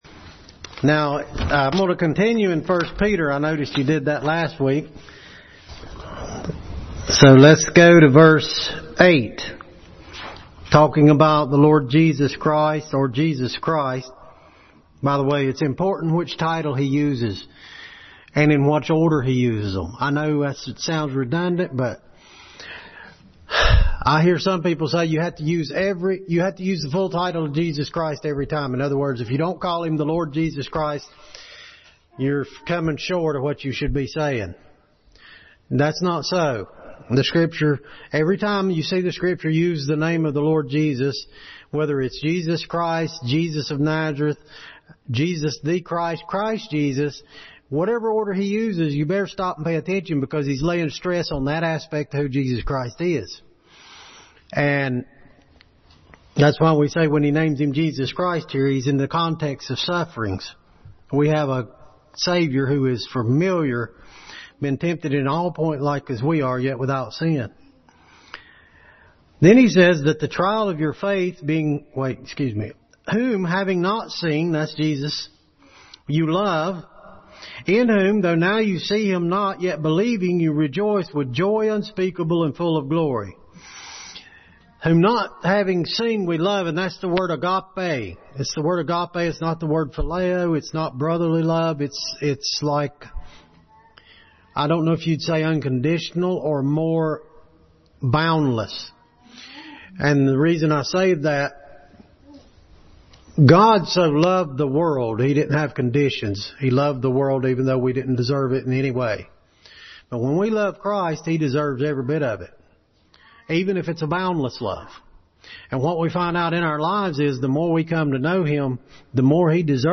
1 Peter 1:8-13 Passage: 1 Peter 1:8-13, 1 Corinthians 15:3-4 Service Type: Family Bible Hour